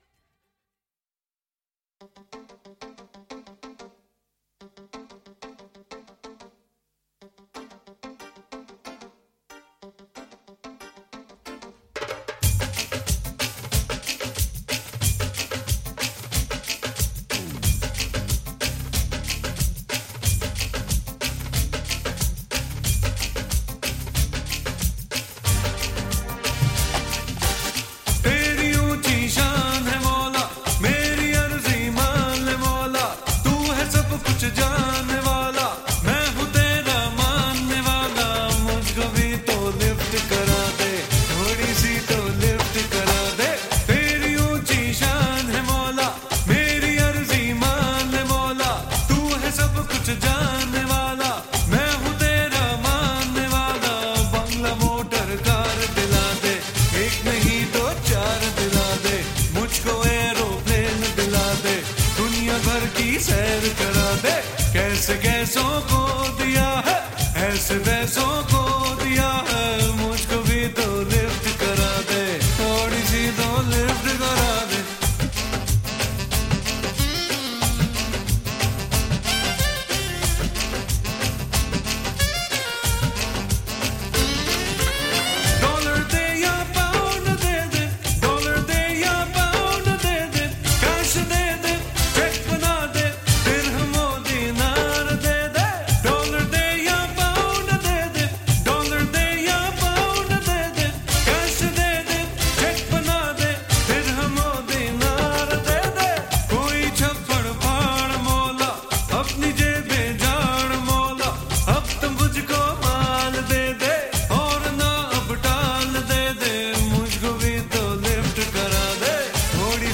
IndiPop Music Album